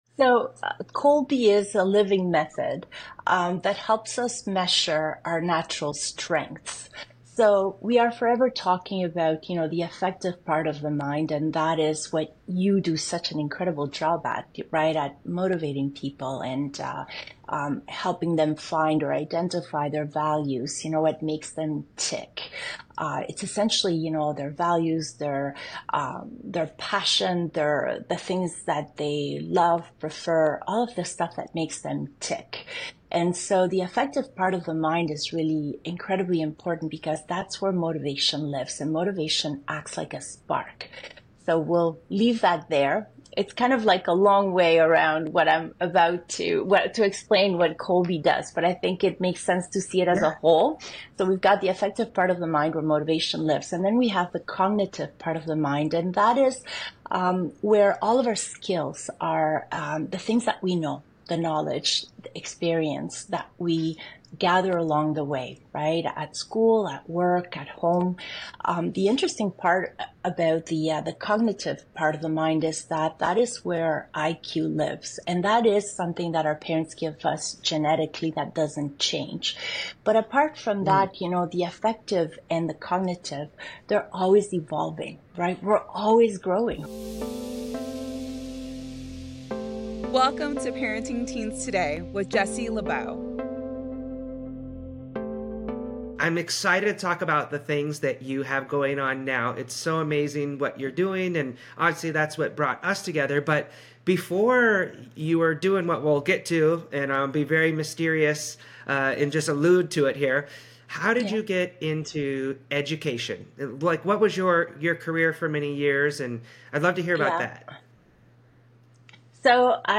Tune in for a transformative conversation that dives deep into overcoming trauma, parenting challenges, and the importance of self-care.